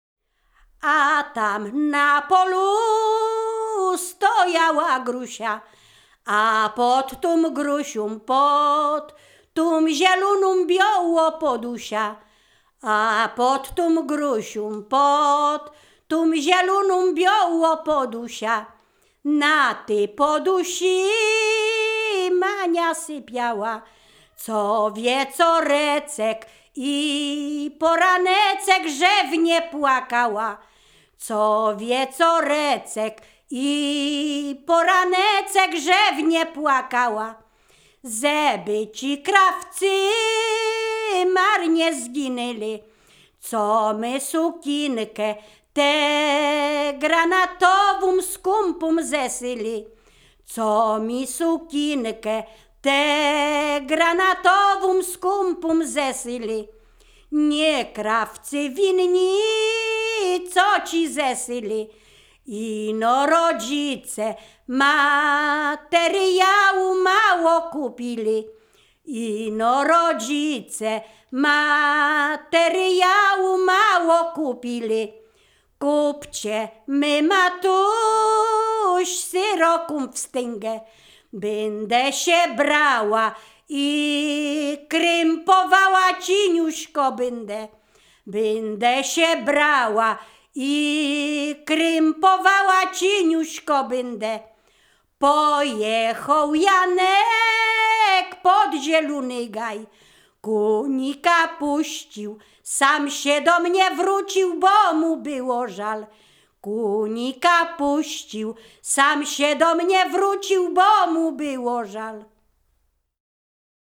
liryczne miłosne weselne